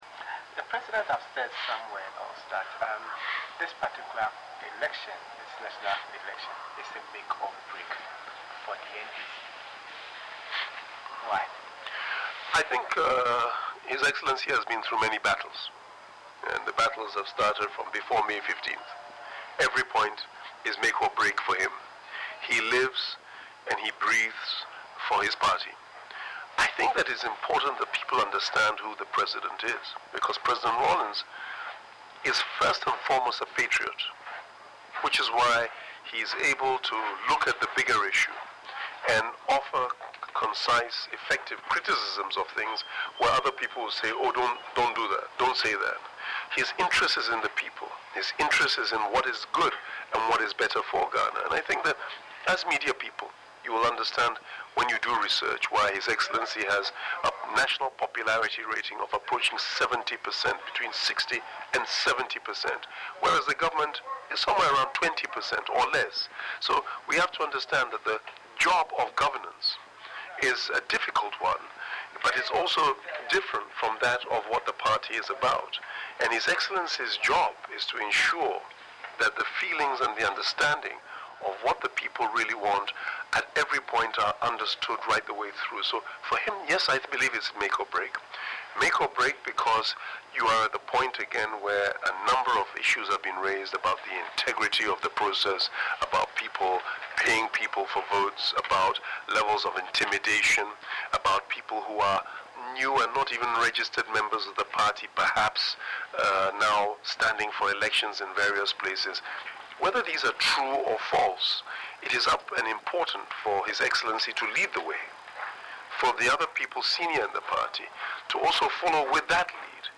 Interview
was held at the NDC National Delegates Conference in Tamale.